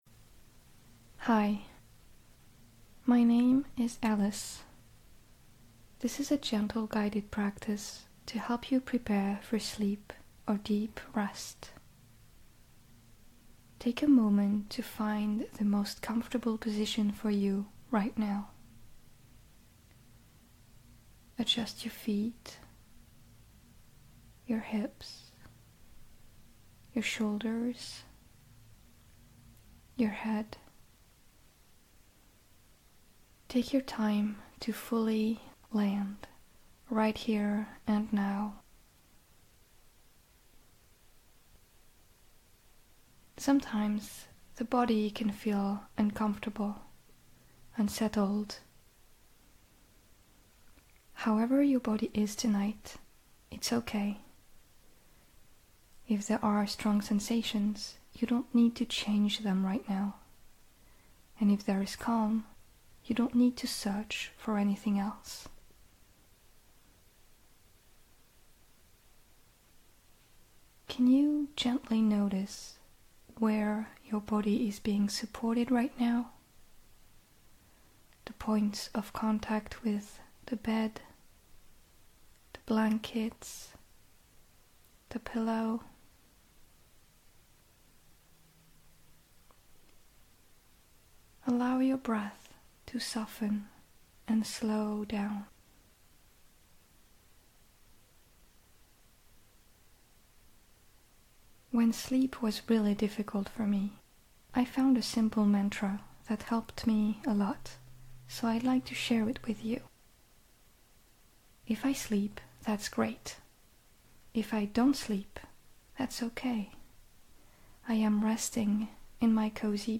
A small collection of short, soft and accessible guided regulation practices, inspired by what helped me in my own journey.
I created them to be accessible to all levels of severity: short (2 to 5 minutes), ad-free, without ambient sounds or music, and free to download.
Hello-Self-Meditation_Sleep-EN.m4a